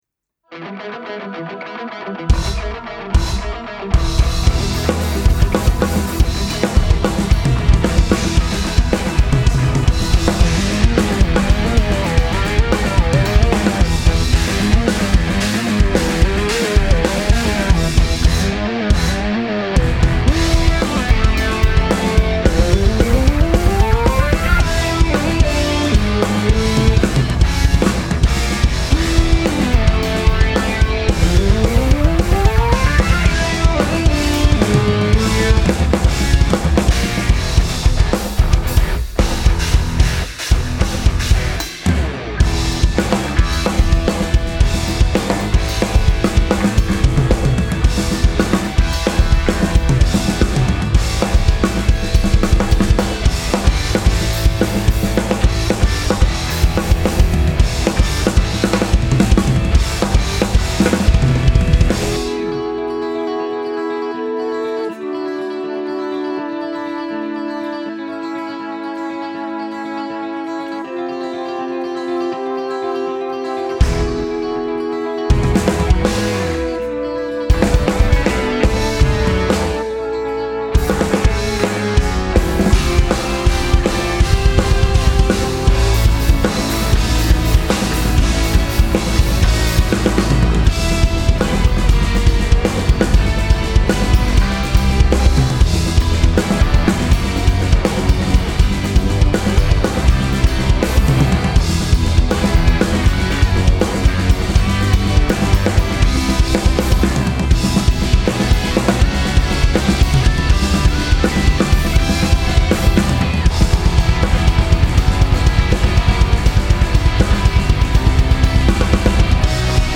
Punk Prog Drums Recording Rock